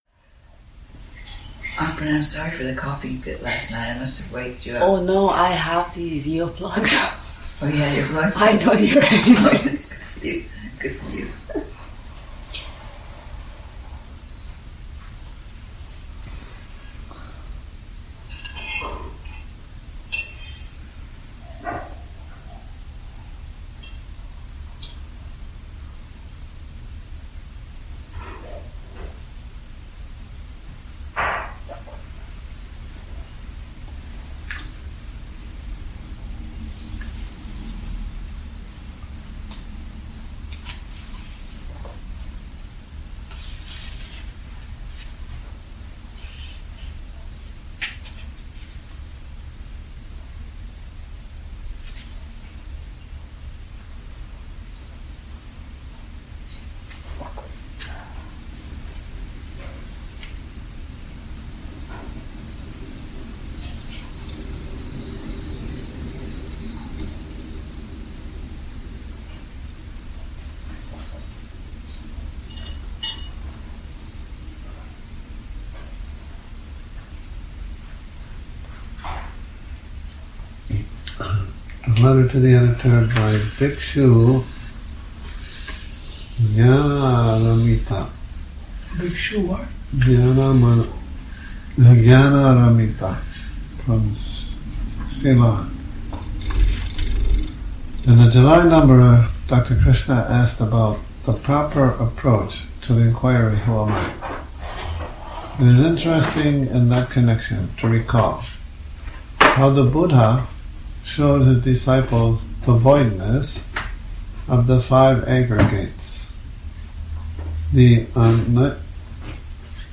Morning Reading, 01 Nov 2019
there's a bit of page turning and a little banter between.